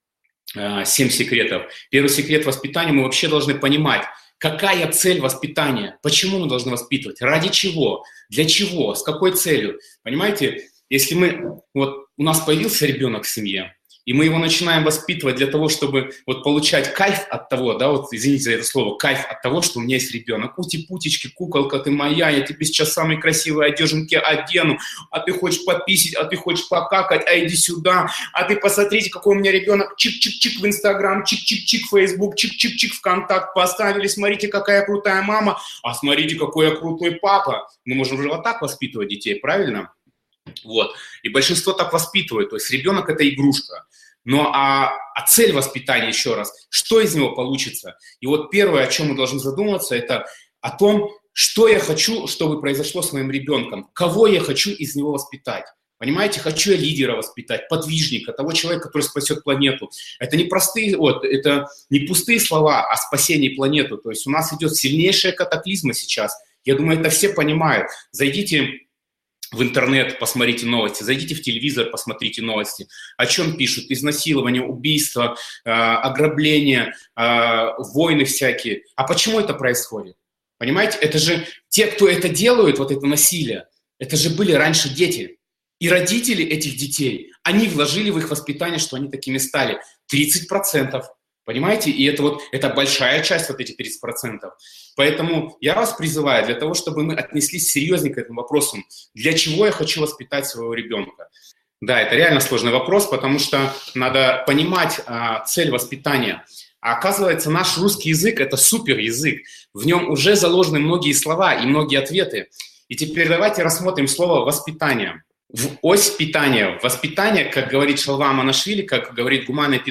Aудиокнига 7 ведических секретов воспитания счастливого ребенка.
Читает аудиокнигу